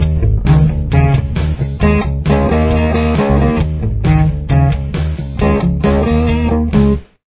Main Riff